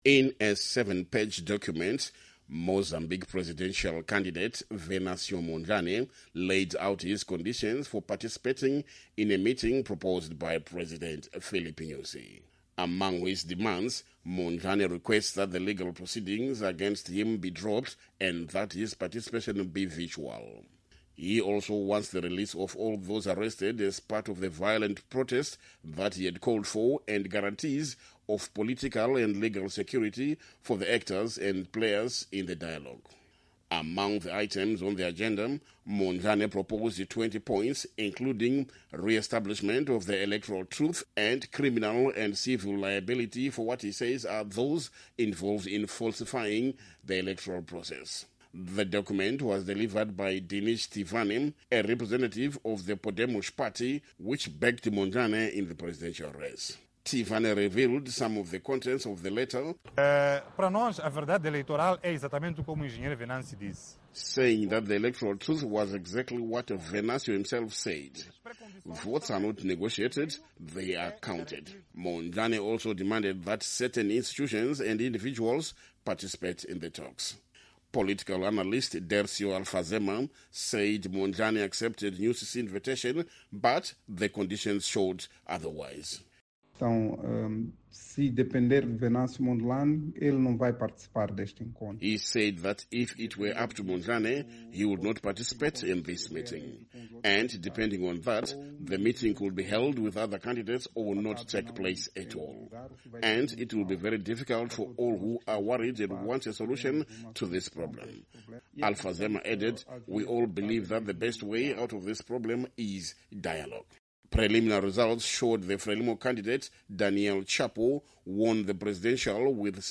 reports from Maputo